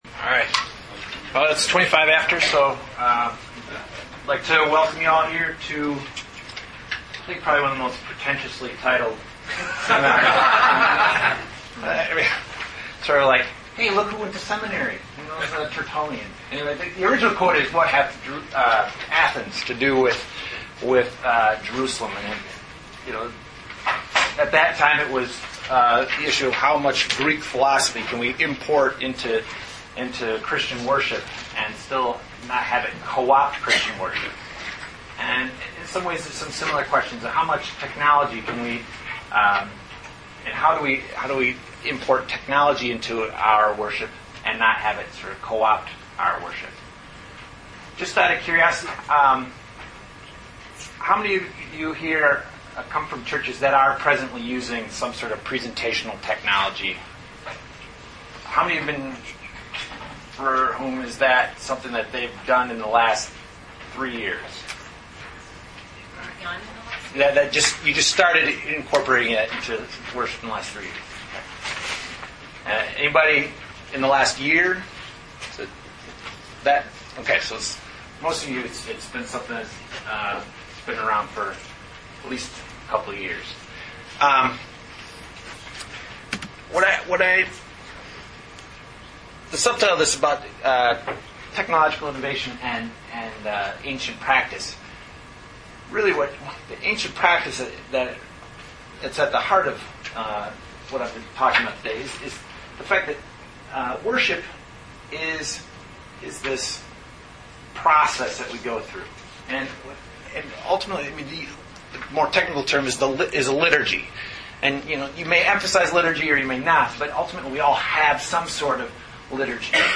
Presented at the 2008 Calvin Symposium on Worship.